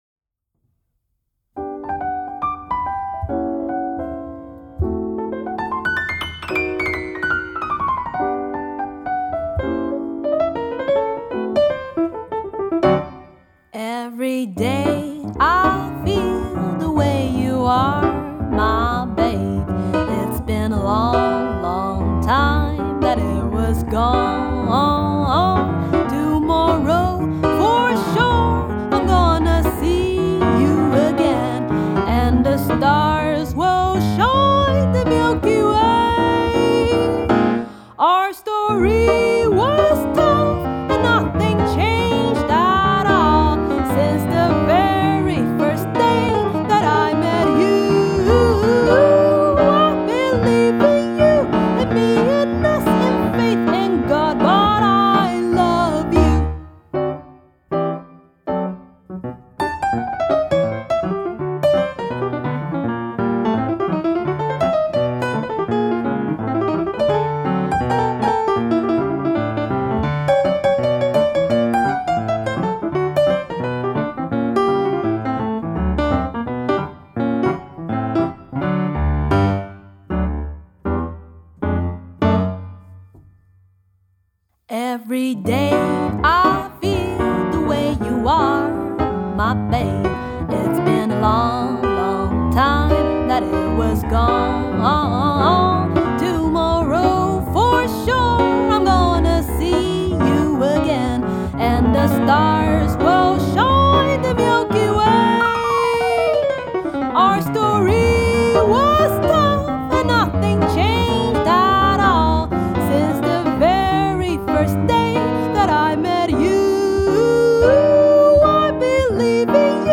Pianiste
piano
jazz